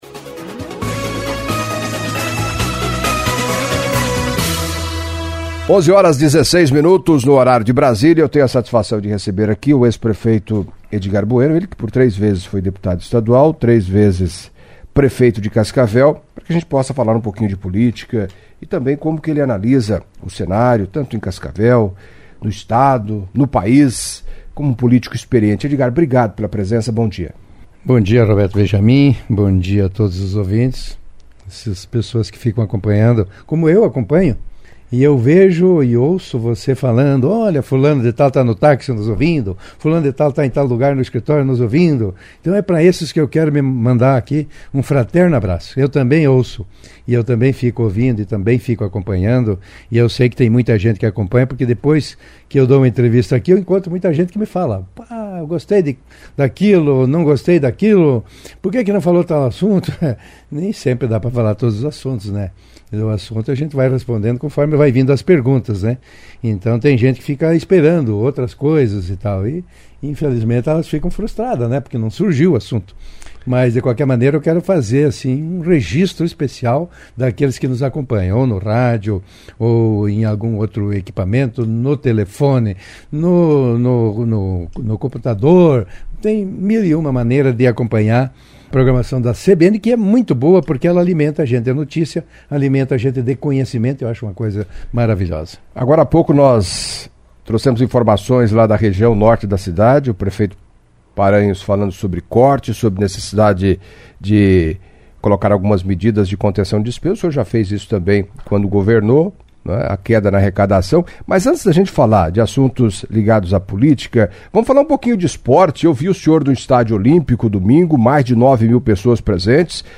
Em entrevista à CBN nesta terça-feira (21) o ex-prefeito, Edgar Bueno, demonstrou forte interesse em concorrer à prefeitura de Cascavel em 2024.